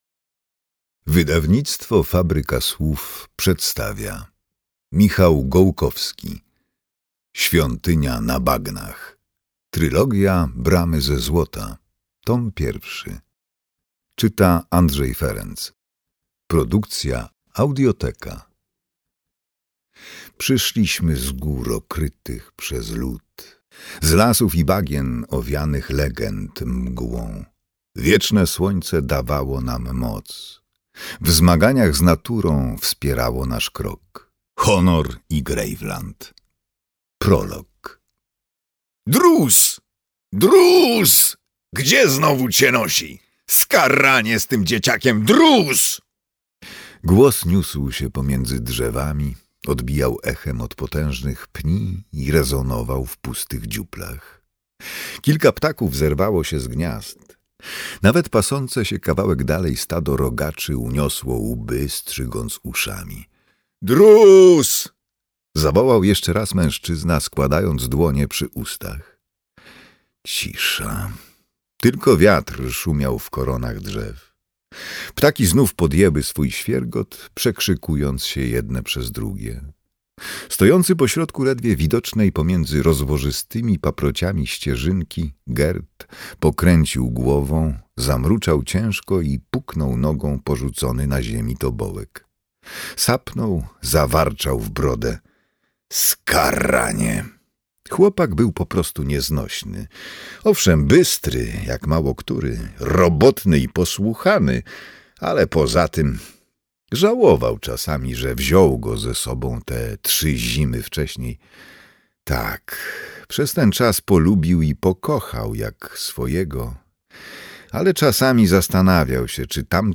Świątynia na bagnach. Trylogia Bramy ze złota. Tom 1 - Michał Gołkowski - audiobook